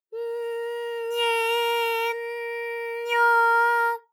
ALYS-DB-001-JPN - First Japanese UTAU vocal library of ALYS.
ny_J_nye_J_nyo.wav